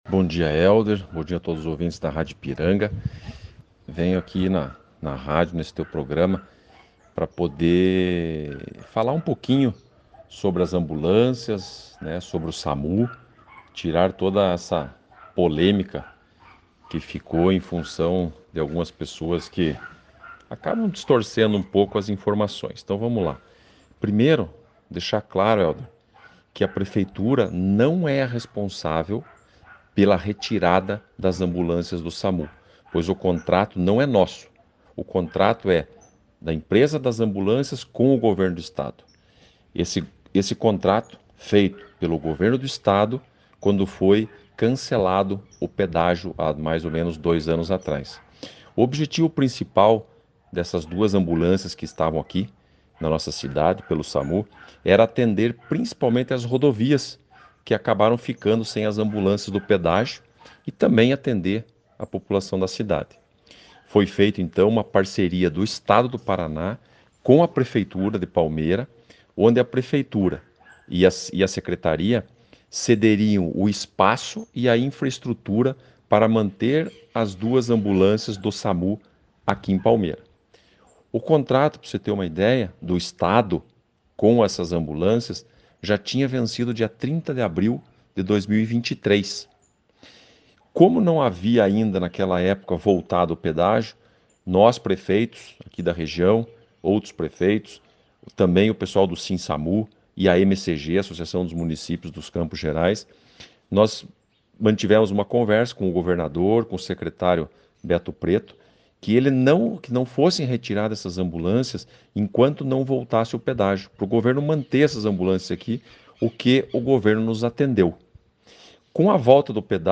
O prefeito Sergio Belich falou a Ipiranga FM, sobre este assunto e reforçou “Palmeira não perdeu o Samu”.